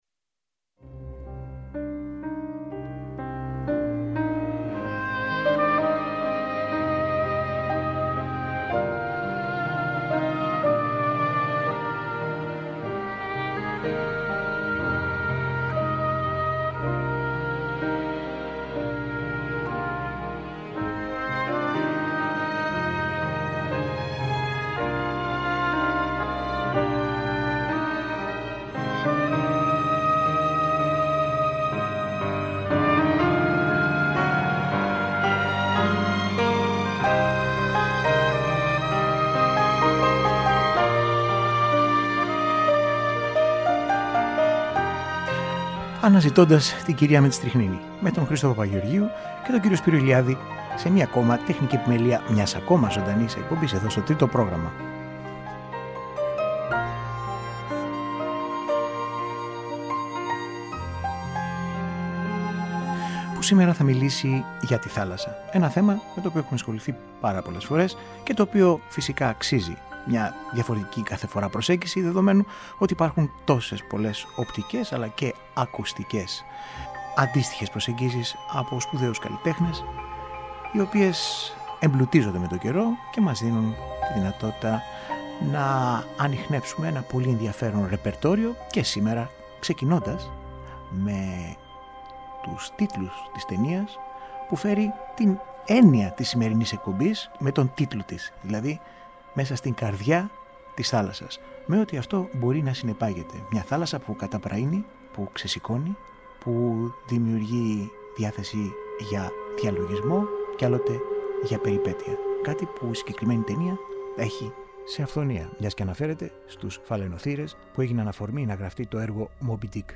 Διάφορα μουσικά θέματα του κινηματογράφου με αφορμή ταινίες με ποικίλα θέματα για τη θάλασσα.